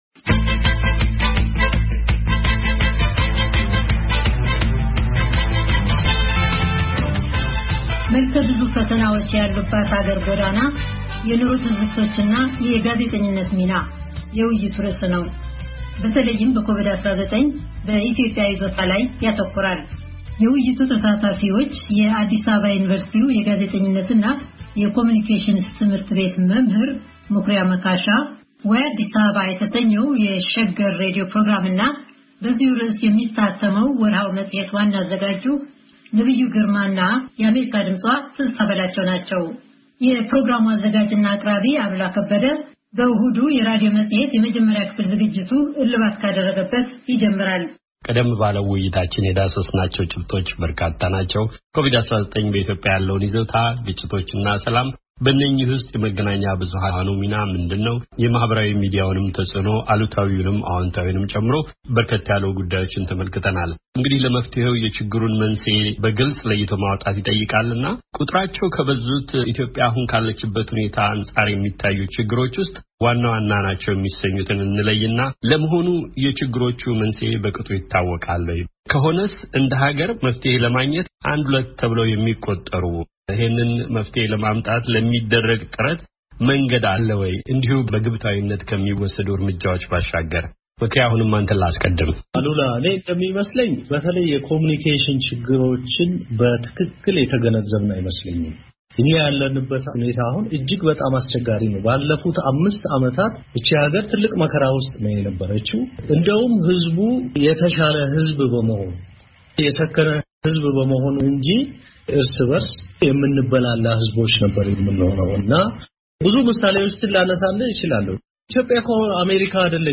በሕብረተሰብ ጤና እና ደህንነት ላይ የተደቀኑ መልከ-ብዙ ሰሞንኛ ፈተናዎችን እና የመገናኛ ብዙሃንን ሚና የሚፈትሽ ውይይት ነው።